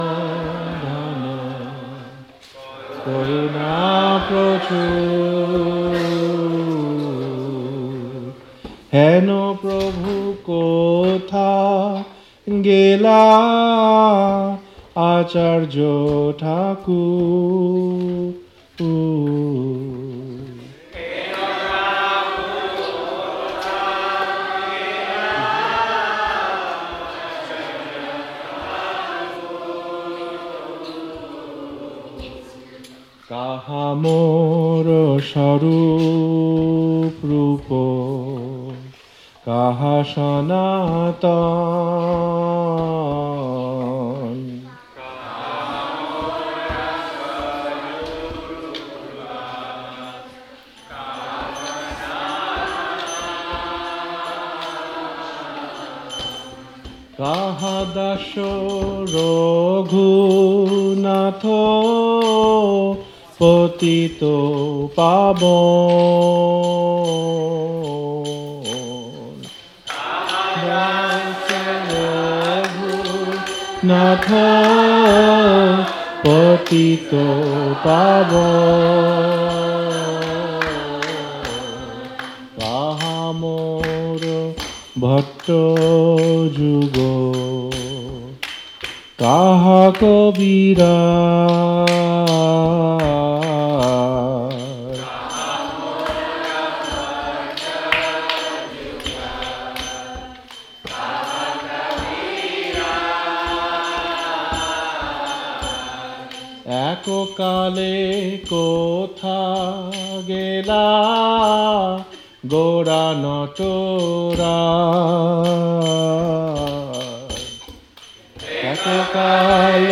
Lectures and kirtanas (devotional music) from the Hare Krishna temple in Alachua, Florida.